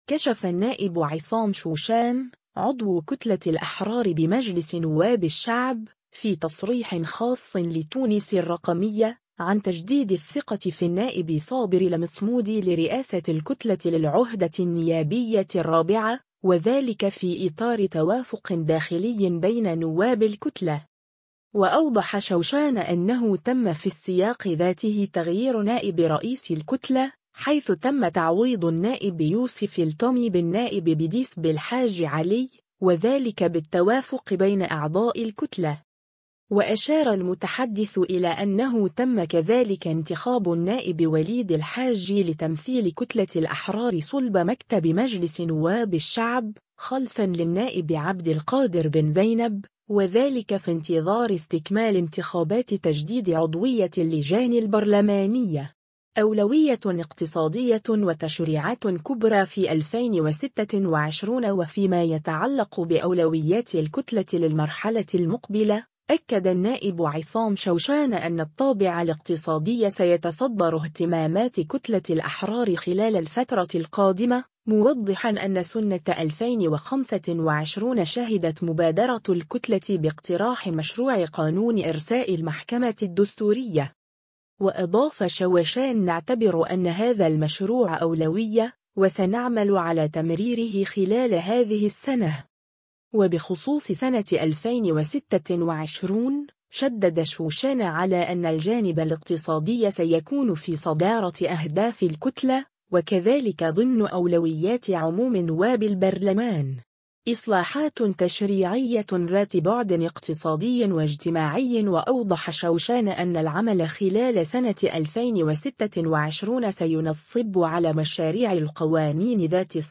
كشف النائب عصام شوشان، عضو كتلة الأحرار بمجلس نواب الشعب، في تصريح خاص لـ”تونس الرقمية”، عن تجديد الثقة في النائب صابر المصمودي لرئاسة الكتلة للعهدة النيابية الرابعة، وذلك في إطار توافق داخلي بين نواب الكتلة.